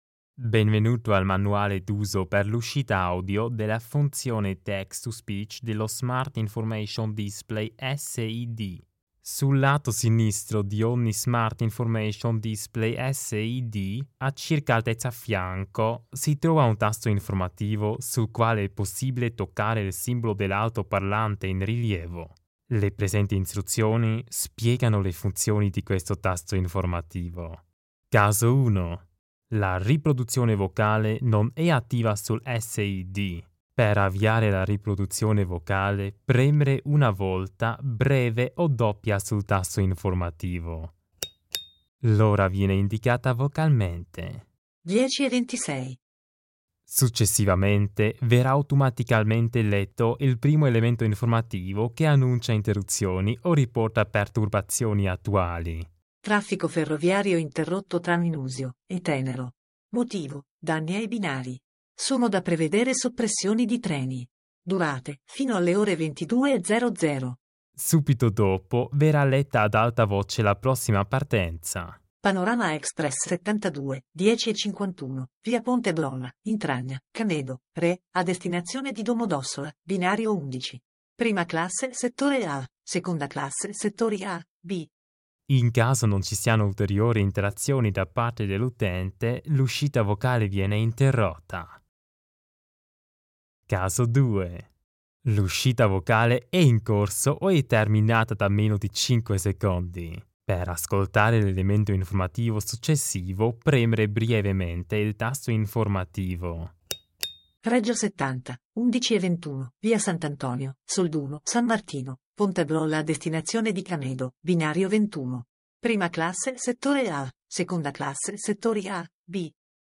Istruzioni audio funzione di lettura ad alta voce (MP3, 4,6 MB)accessibility.barrierfree (MP3, 4,6 MB)